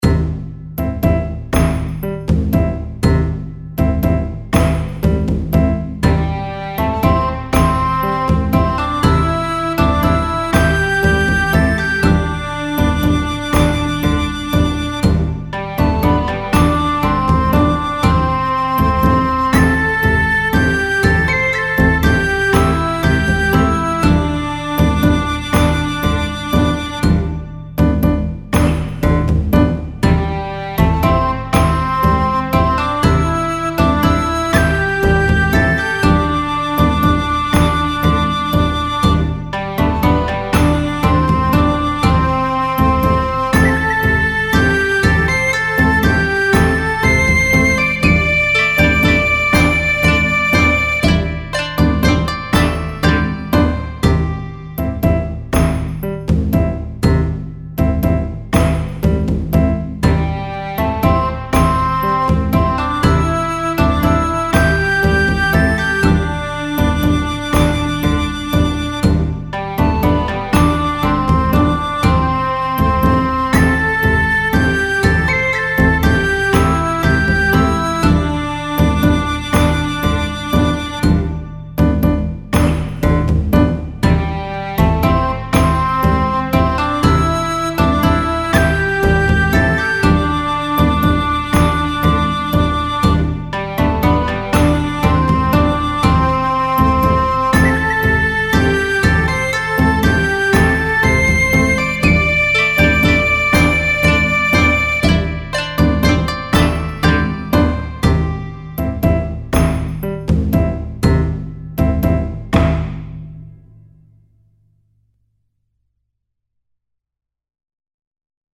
琴や三味線、太鼓、尺八などを用いた和風のBGMです。
ほのぼのした田舎風景。